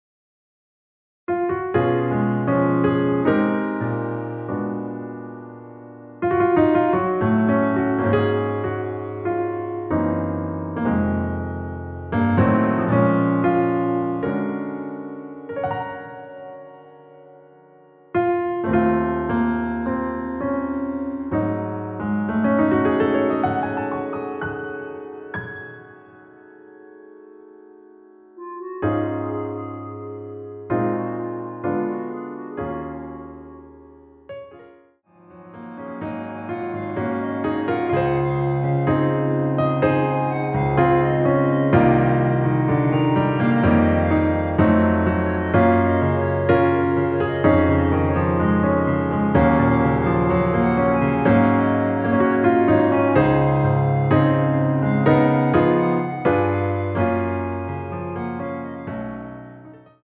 원키에서(-3)내린 멜로디 포함된 MR입니다.
Db
앞부분30초, 뒷부분30초씩 편집해서 올려 드리고 있습니다.